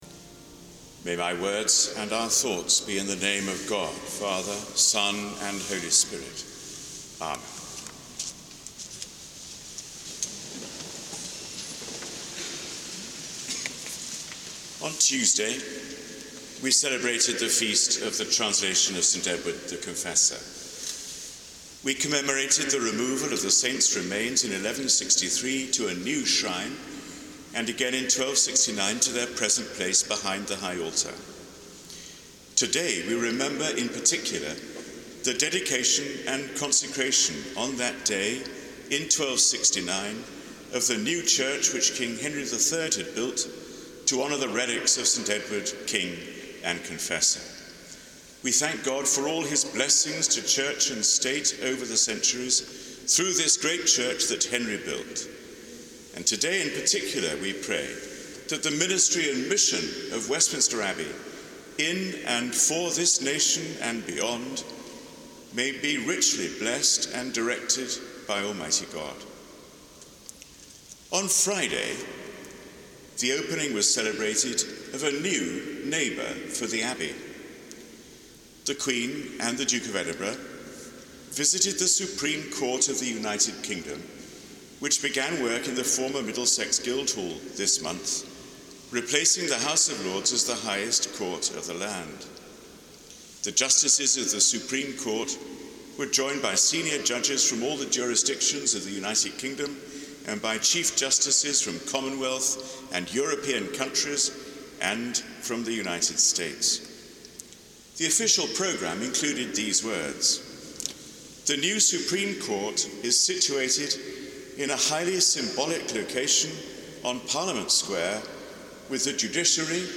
Sermon on the Abbey’s Dedication Festival: Sunday 18 October
On the Abbey’s dedication festival, the Dean of Westminster identified the ultimate purpose of the Abbey as to give greater glory to God. That test must be applied to the Abbey’s daily life and work and to any proposed changes.